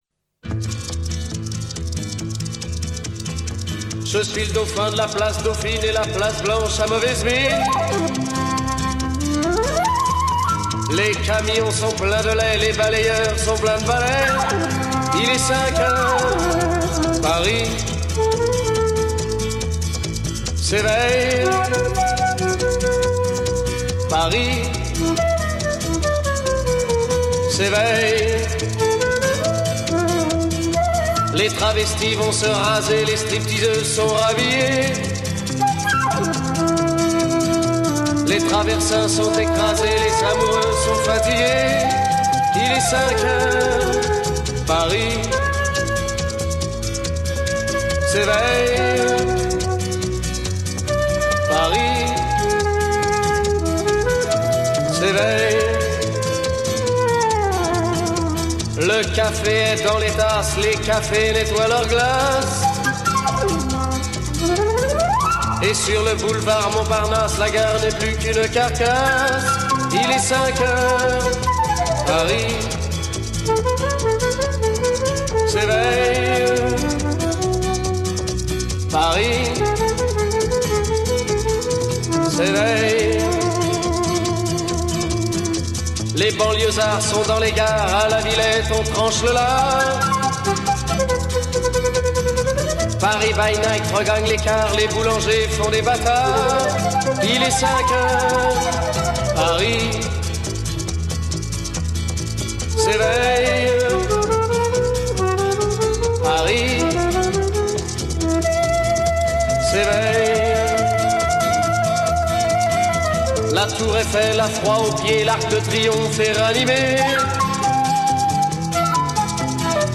On entend déjà dans la nuit d’un matin récalcitrant le bruit de quelques moteurs qui commencent à s’agiter sur les avenues de la ville tandis que les tramways remplissent déjà leur office… « Il est 5h00 Lyon s’éveille ». Il est 5h00, il fait humide et frisquet en ville en ce début de mois de novembre.